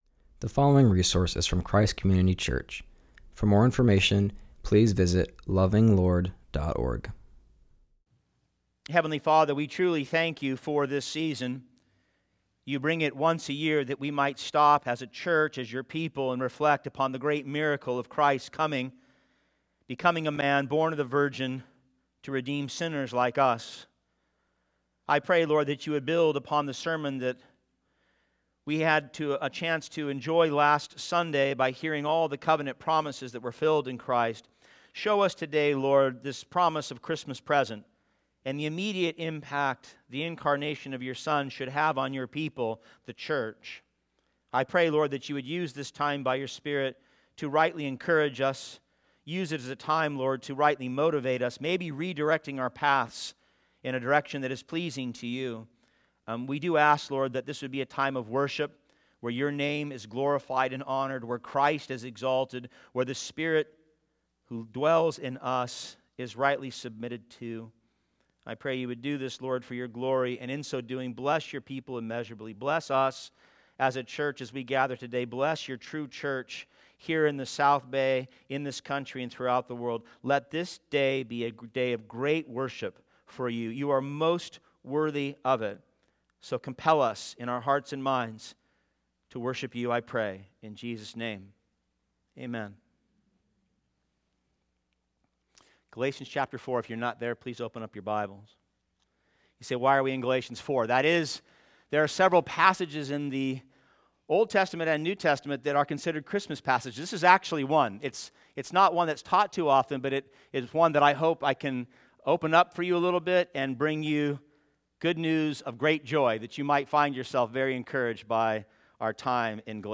preaches about Christmas Present using various passages.